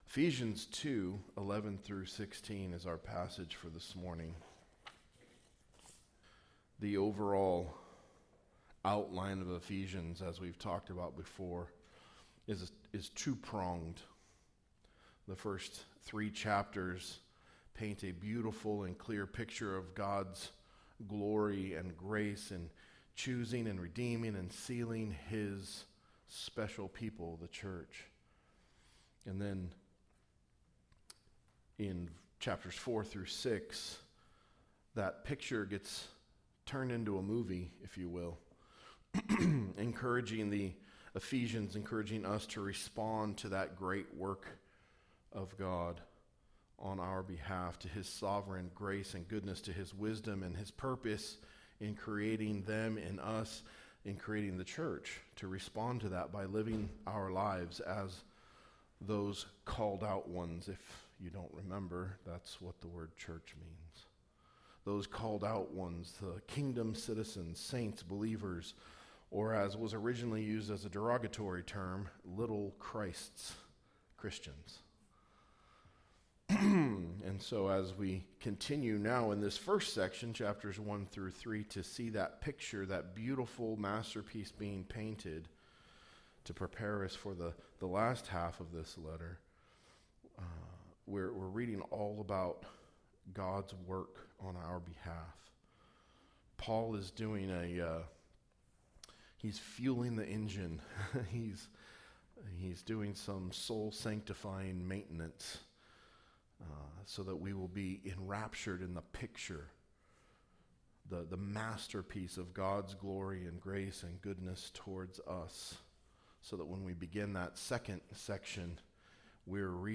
A message from the series "Ephesians."